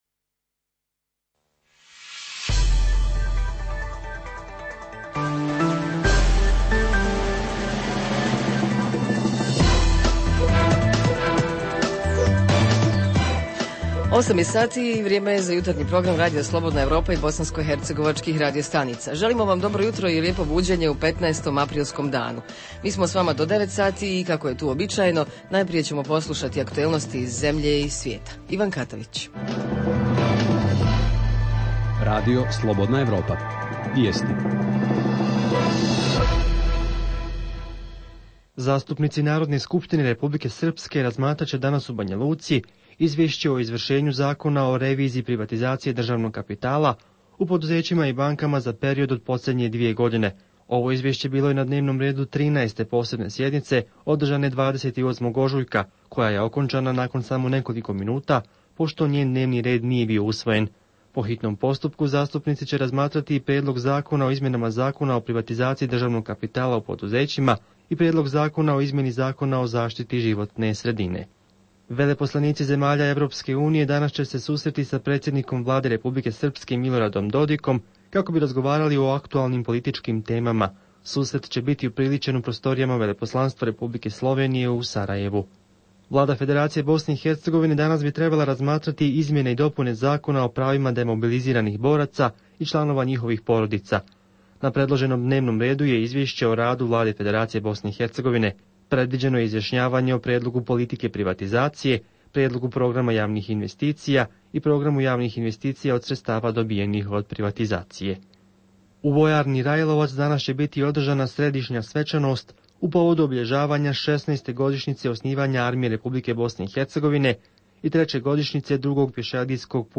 Redovna rubrika Radija 27 utorkom je "Svijet interneta". Redovni sadržaji jutarnjeg programa za BiH su i vijesti i muzika.